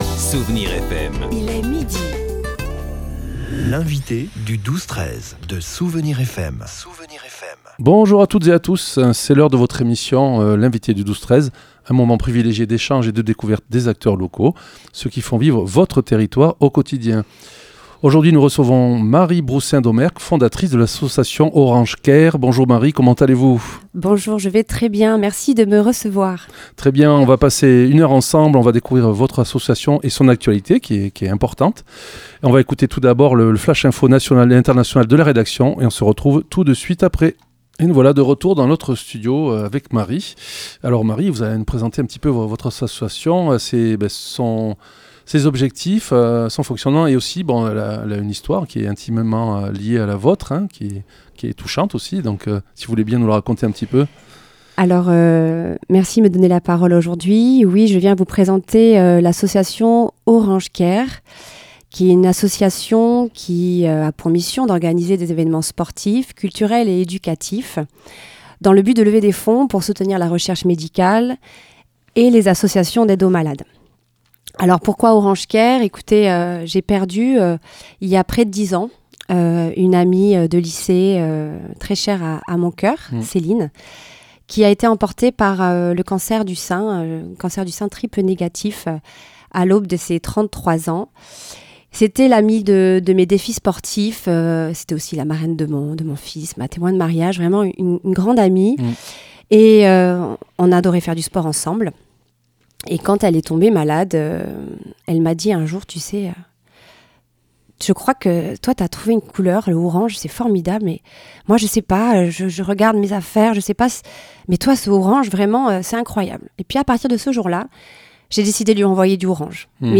Sur les ondes de Souvenirs FM le 12-13 de Soustons